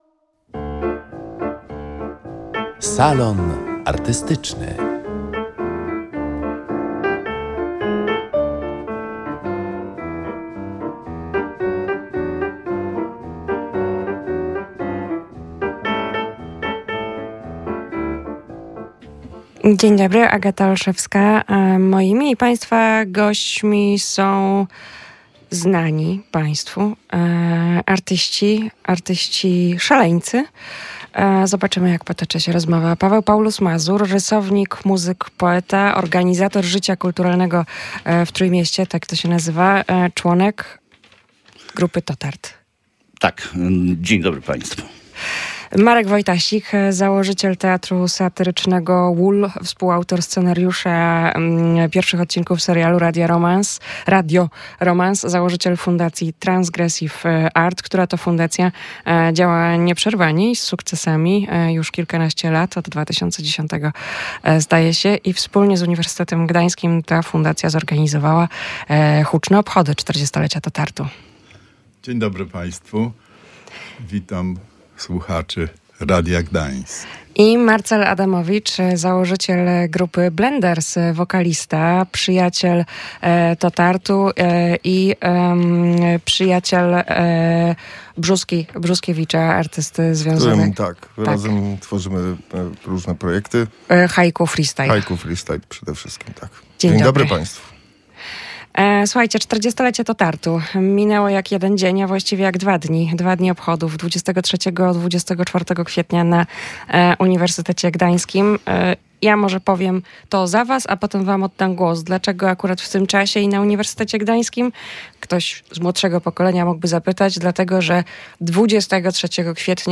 W Salonie Artystycznym związani z Tranzytoryjną Formacją artyści opowiadają o kulisach jej powstania, o słynnej metodzie „zlewu” i o tym, czy zdarzało im się nie rozumieć własnej sztuki.